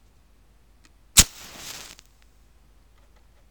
Streichholz
Das anreißen eines Streichholz verursacht ein sehr charakteristisches Geräusch, welches bewusst in Filmen und Hörspielen eingesetzt wird um ein Zigaretten- oder Feuer-anmachen zu verdeutlichen.
streichholz